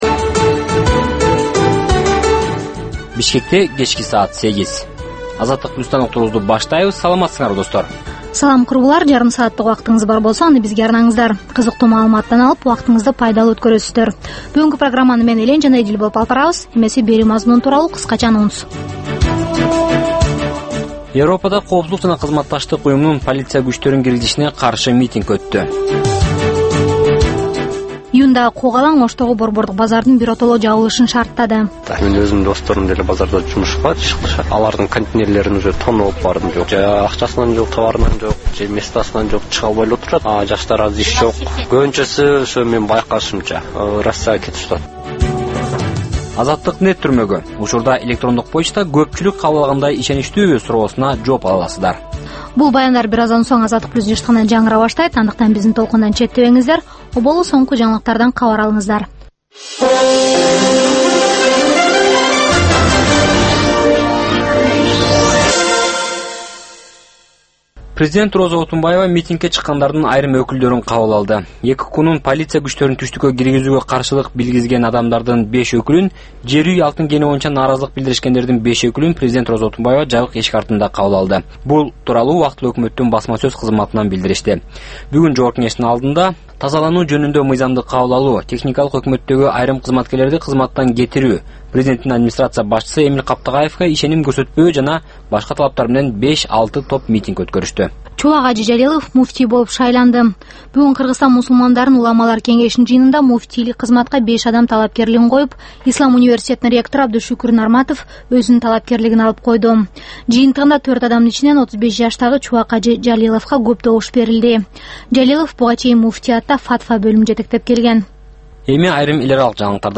Бул жаштарга арналган кечки үналгы берүү жергиликтүү жана эл аралык кабарлардын чакан топтому, ар кыл репортаж, сереп, маек, маданий, спорттук, социалдык баян, тегерек үстөл четиндеги баарлашуу жана башка кыргызстандык жаштардын көйгөйү чагылдырылган берүүлөрдөн турат. "Азаттык үналгысынын" бул жаштар берүүсү Бишкек убакыты боюнча саат 20:00дан 20:30га чейин обого түз чыгат.